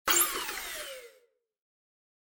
جلوه های صوتی
دانلود صدای ربات 70 از ساعد نیوز با لینک مستقیم و کیفیت بالا